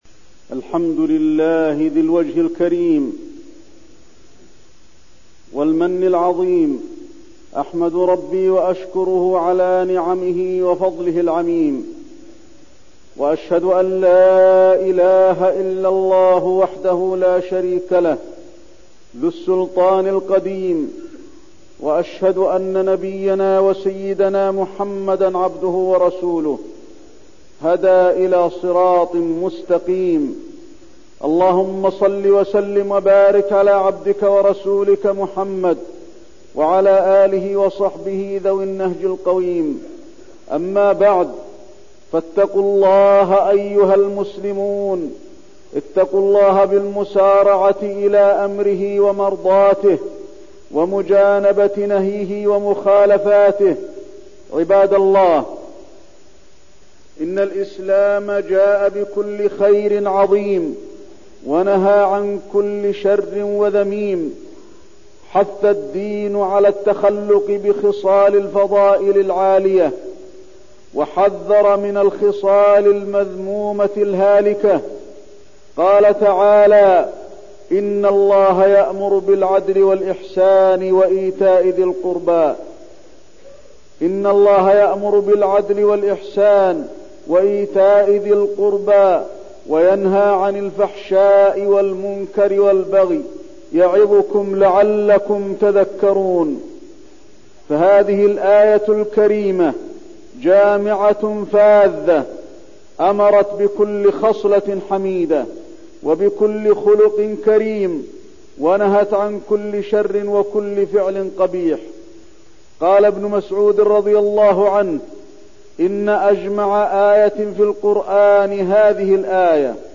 تاريخ النشر ٢١ جمادى الآخرة ١٤١٢ هـ المكان: المسجد النبوي الشيخ: فضيلة الشيخ د. علي بن عبدالرحمن الحذيفي فضيلة الشيخ د. علي بن عبدالرحمن الحذيفي الحسد